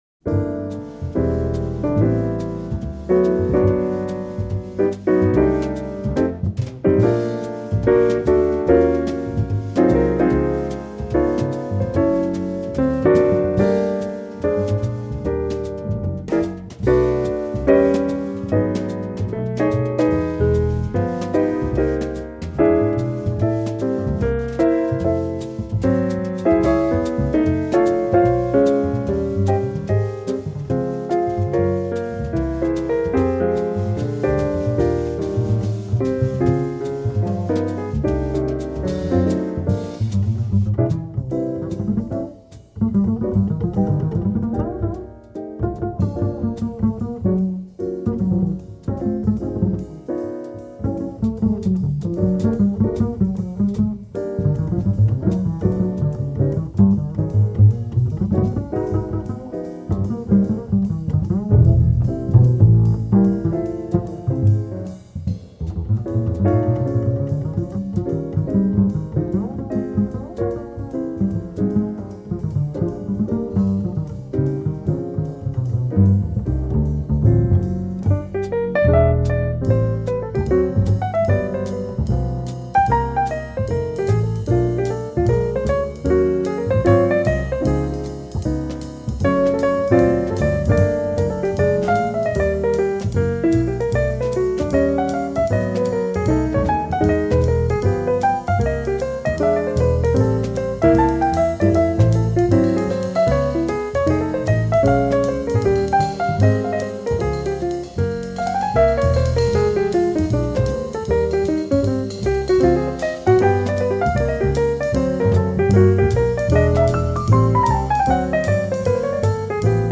JAZZ PIANO SOLOS - AUDIO FOR SOLO TRANSCRIPTIONS